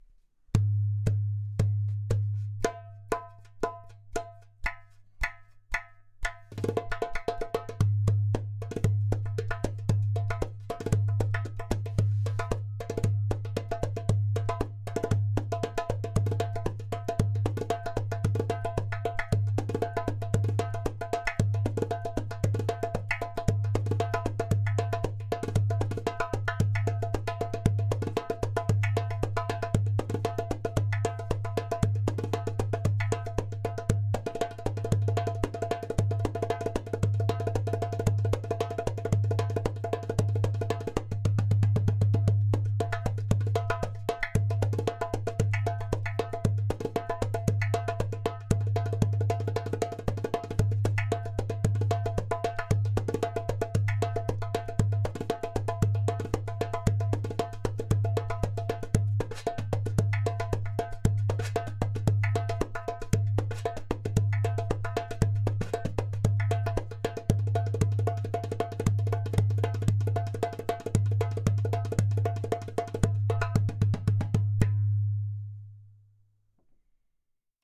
• Strong and easy to produce clay kik (click) sound
• Beautiful harmonic overtones.
• Thin goat skin (0.3mm)
• Body: Ceramic / Clay